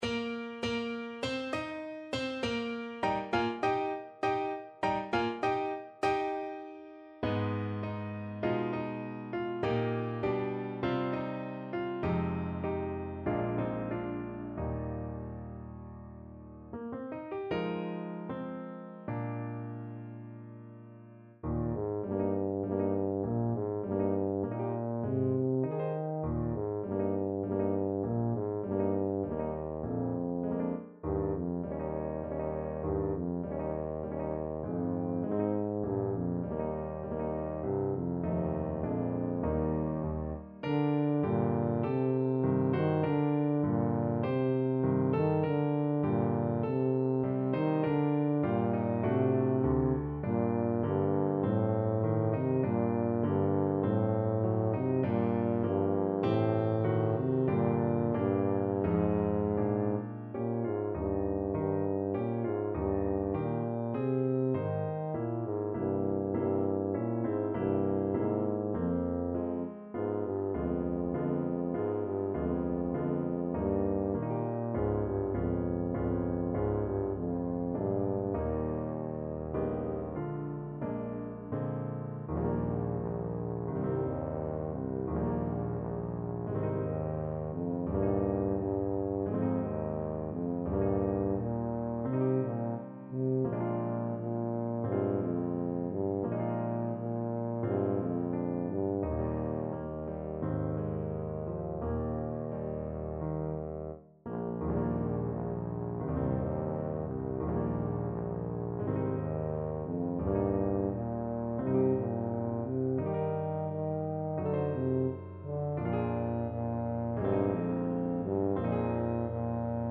4/4 (View more 4/4 Music)
Moderato
Tuba  (View more Intermediate Tuba Music)